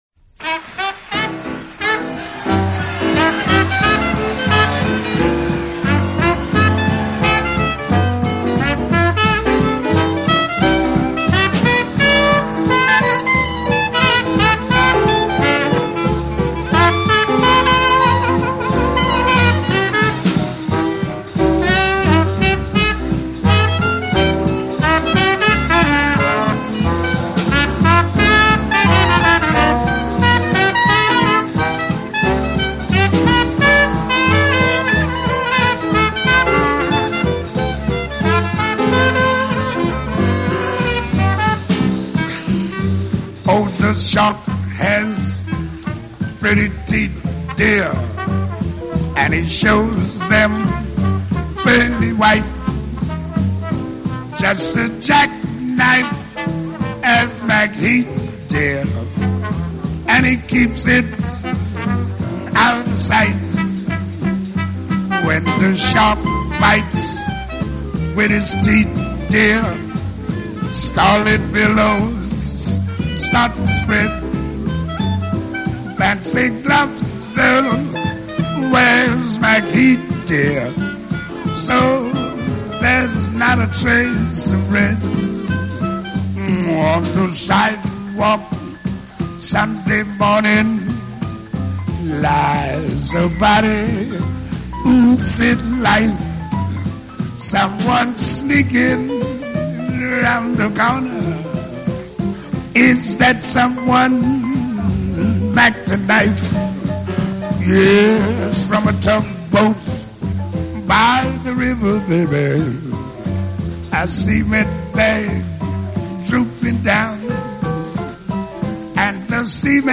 专辑语种： 纯音乐
专辑类型： 爵士